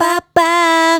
Bah Bahh 120-E.wav